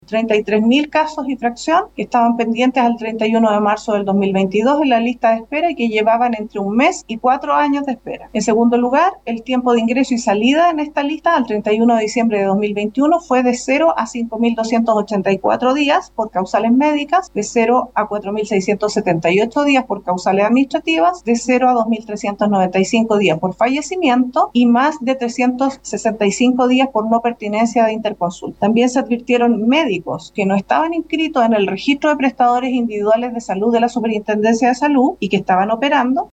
Así lo detalló durante su exposición en la Comisión, la contralora Dorothy Pérez.